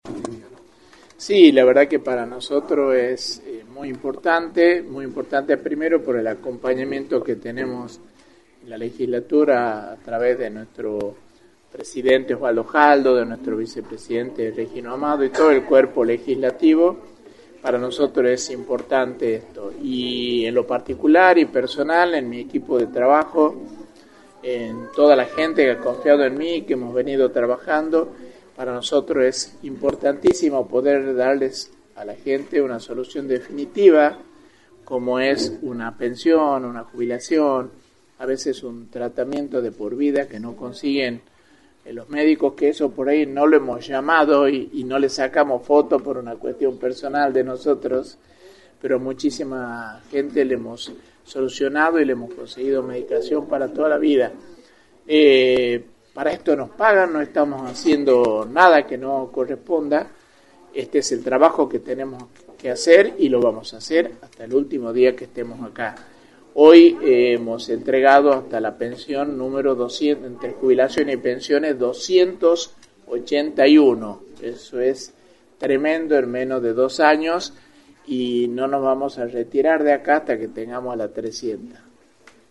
Jorge Delgadino, Legislador por el oficialismo, remarcó en Radio del Plata Tucumán, por la 93.9, las repercusiones de la actividad legislativa de esta semana.
“Para nosotros es importantísimo poder darles a la gente una solución definitiva, como es una pensión, una jubilación, a veces, incluso, un tratamiento de por vida que no consiguen los médicos” señaló Jorge Delgadino en entrevista para “La Mañana del Plata”, por la 93.9.